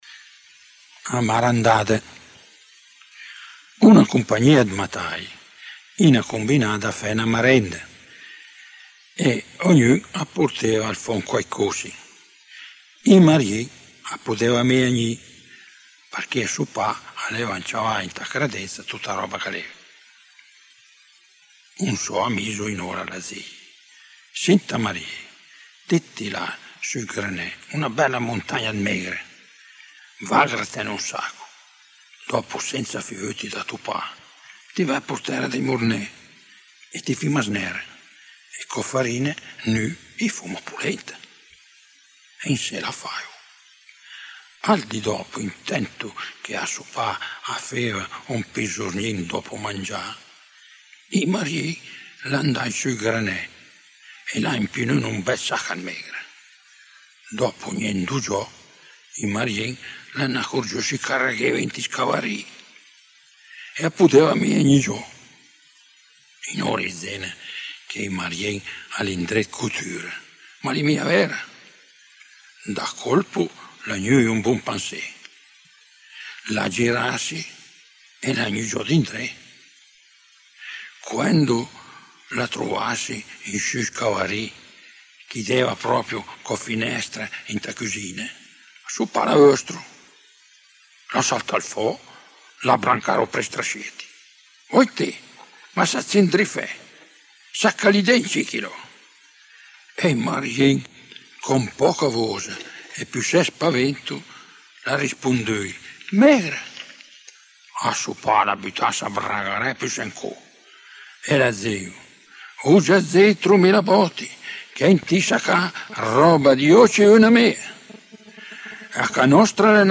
storia umoristica composta e recitata